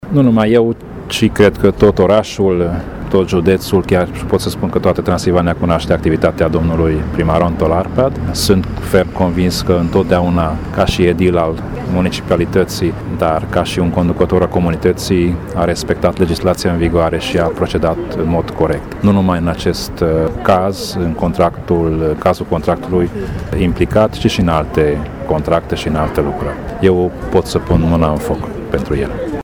Liderul UDMR, Tamas Sandor, presedintele Consiliului Judetean Covasna, mesaj solidaritate cu Antal Arpad: